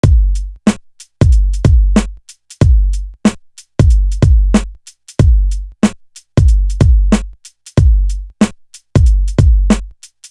Live On Stage Drum.wav